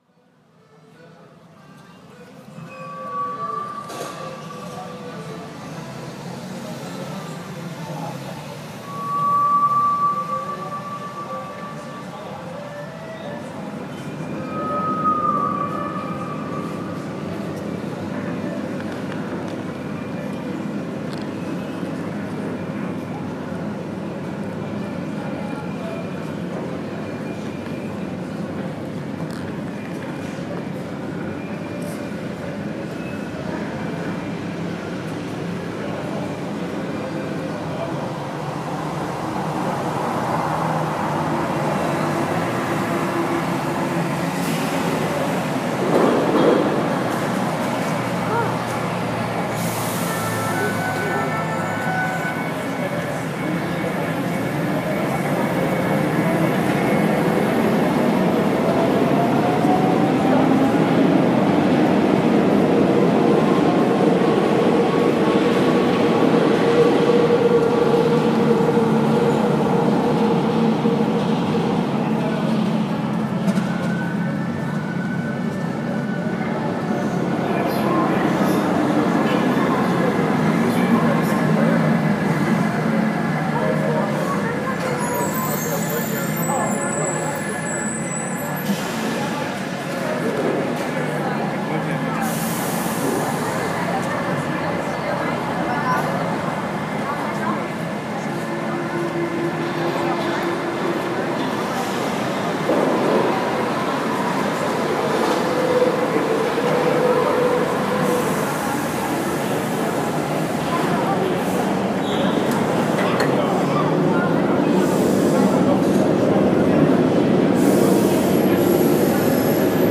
creates an imagined soundscape of Montmartre, Paris, from existing field recordings woven together.